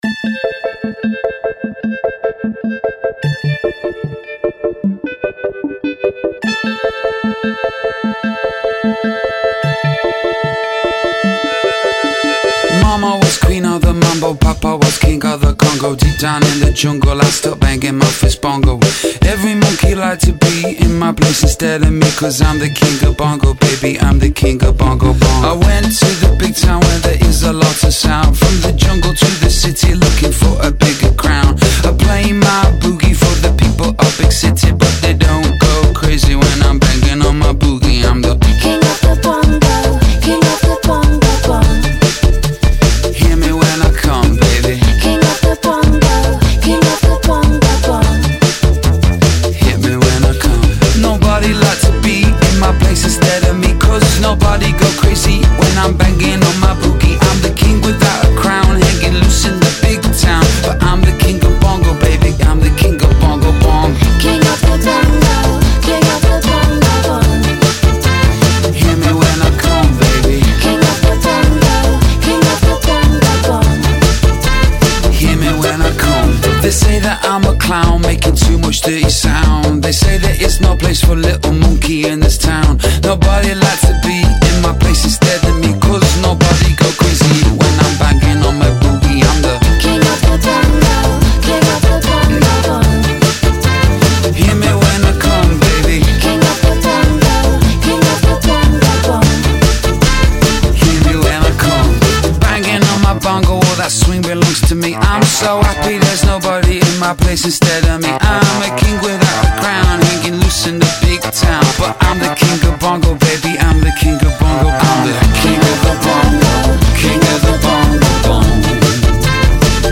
world